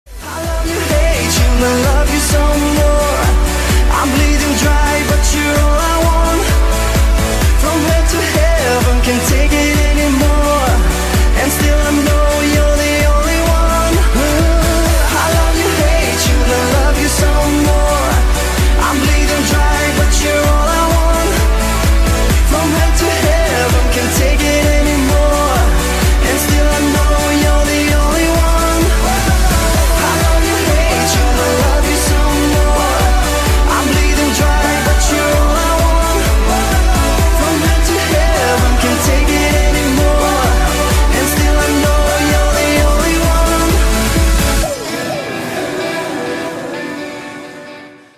• Качество: 128, Stereo
поп
мужской вокал
громкие
dance
Electronic
EDM
электронная музыка
house